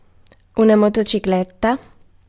Ääntäminen
UK : IPA : [ˈməʊ.tə.baɪk]